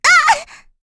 Rehartna-Vox_Damage_kr_04.wav